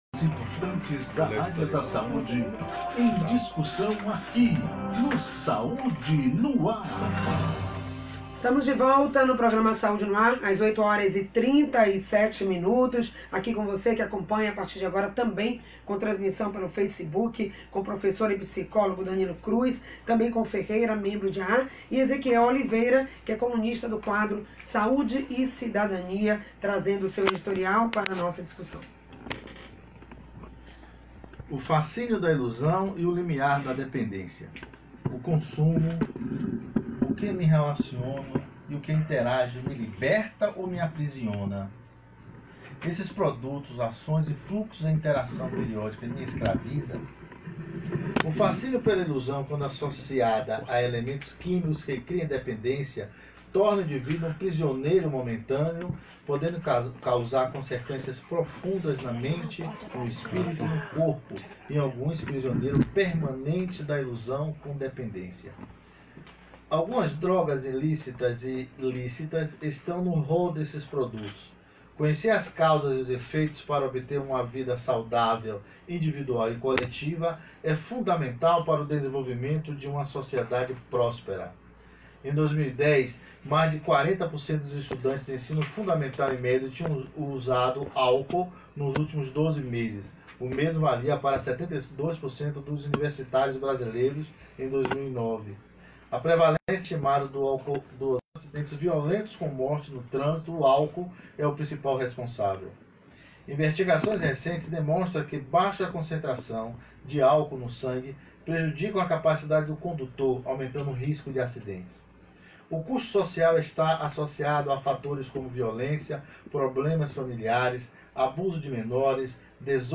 Tema: Uso Abusivo do Álcool e suas consequências. Ouça a entrevista: